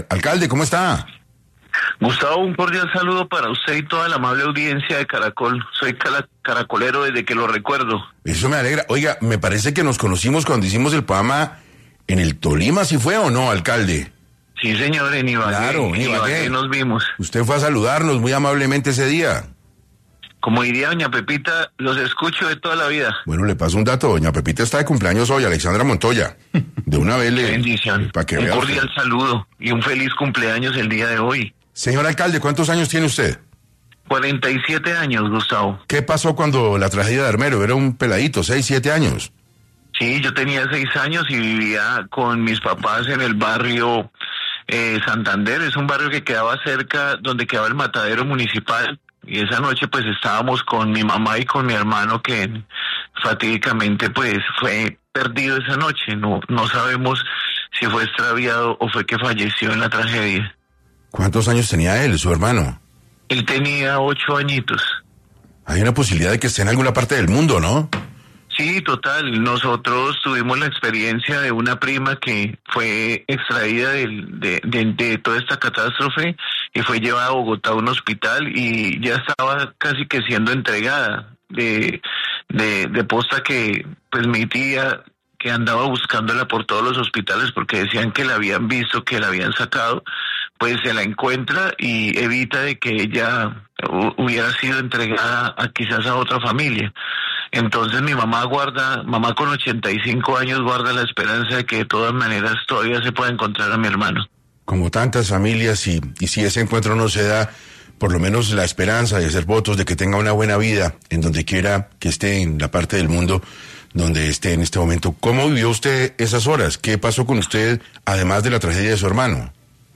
Mauricio Cuellar, alcalde de Armero Guayabal, habla en 6AM sobre la peor tragedia natural de Colombia.